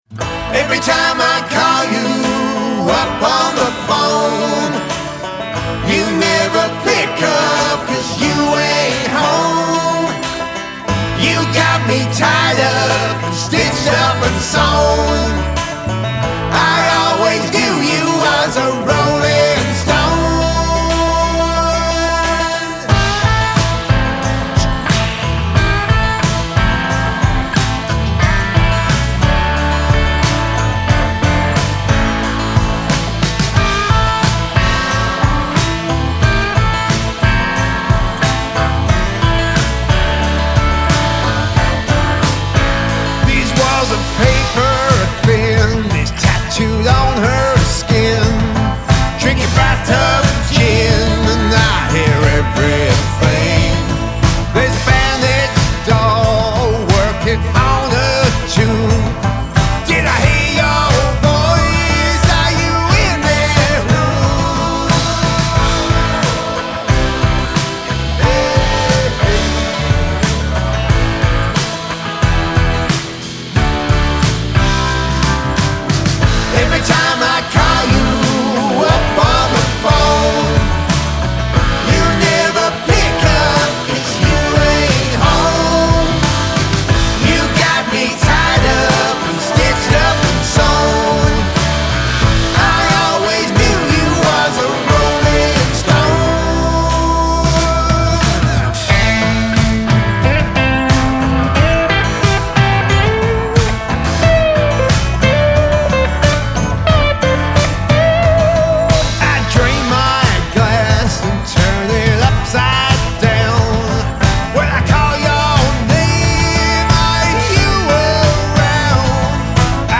Male Vocal, Guitar, Banjo, Bass Guitar, Drums